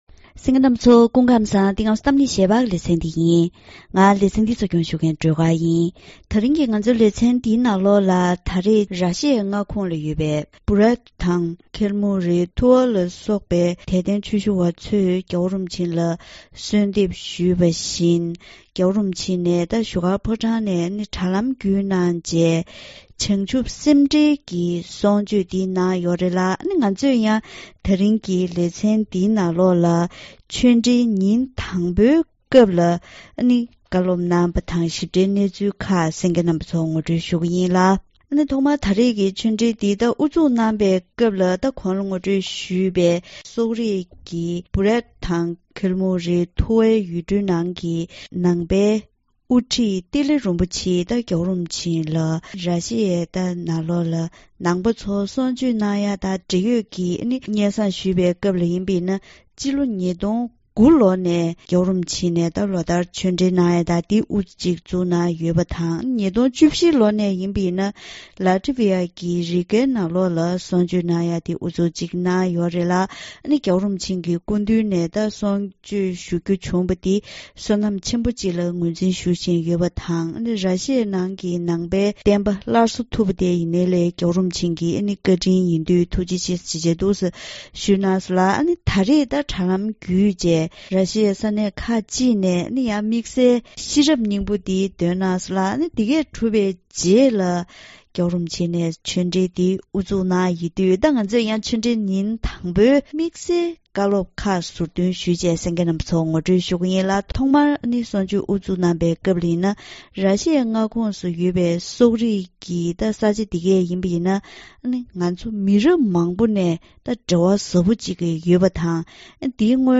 ར་ཤི་ཡའི་ཁོངས་ཀྱི་སྦོ་རས་ཐུ་དང་ཁལ་མུག ཐུ་ཝ་སོགས་ཀྱི་དད་ལྡན་ཆོས་ཞུ་བས་གསོལ་འདེབས་ཞུས་པ་བཞིན་༧གོང་ས་མཆོག་གིས་བྱང་ཆུབ་སེམས་འགྲེལ་གྱི་གསུང་ཆོས།